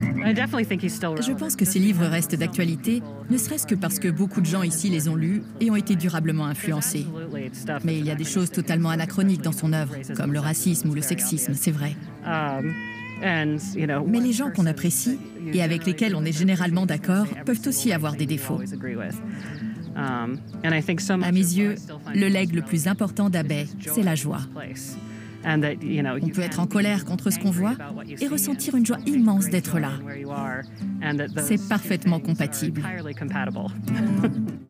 Voice Over Arte voix amicale voix dynamique Voix dynamique Catégories / Types de Voix Extrait : Votre navigateur ne gère pas l'élément video .